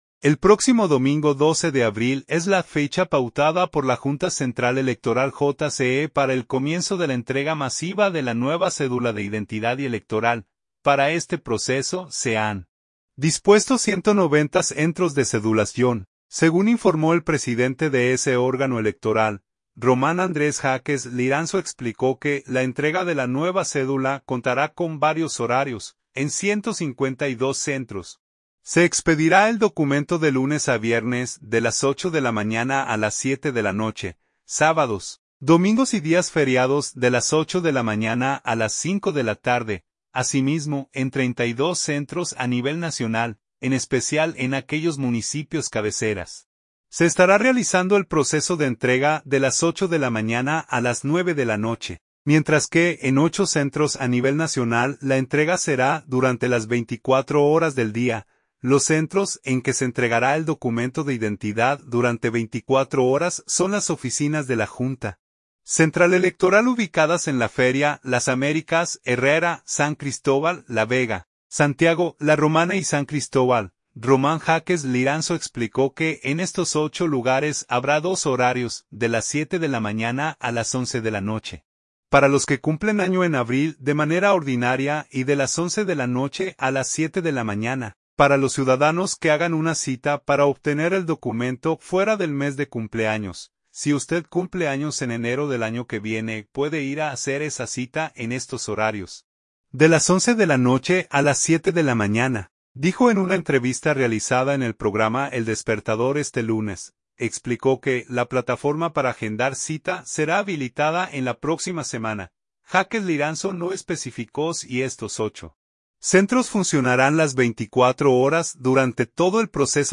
“Si usted cumple años en enero del año que viene, puede ir a hacer esa cita en estos horarios, de 11:00 de la noche a 7:00 de la mañana”, dijo en una entrevista realizada en el programa “El Despertador” este lunes.